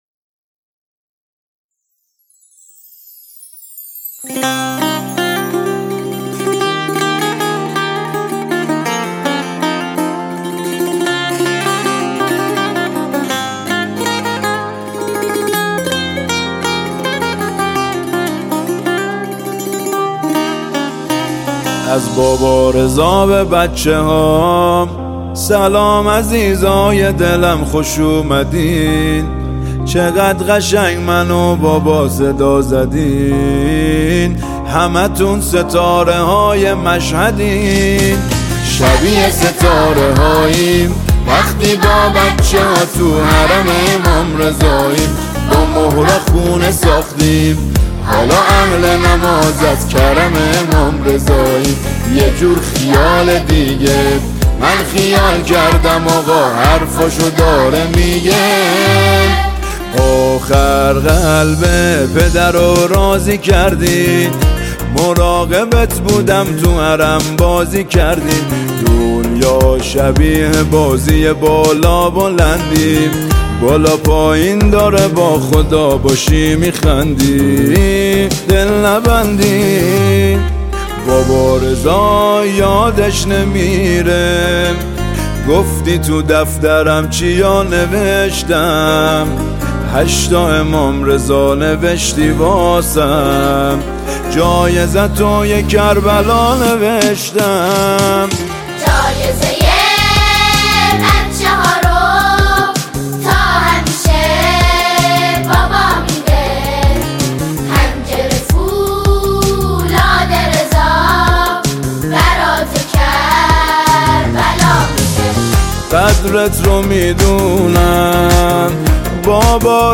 ژانر: سرود ، سرود مناسبتی